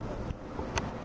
卡特维克的教堂钟声
描述：来自荷兰Katwijk老教堂的教堂大钟